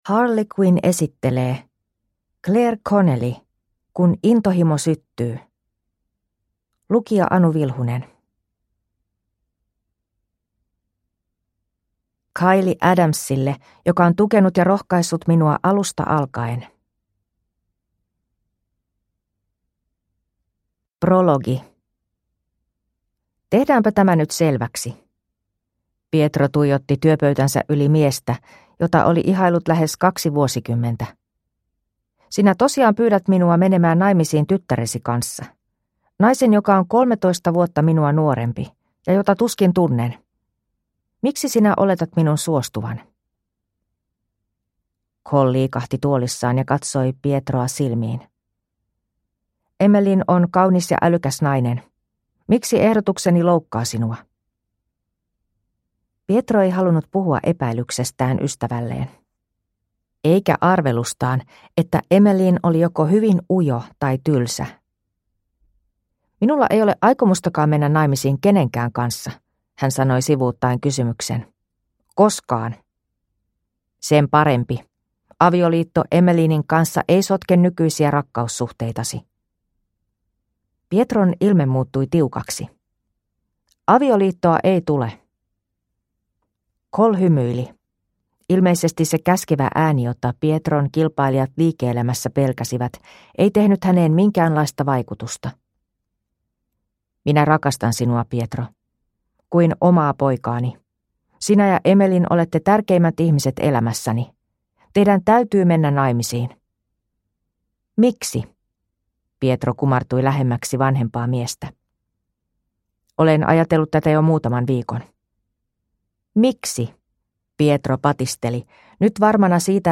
Kun intohimo syttyy – Ljudbok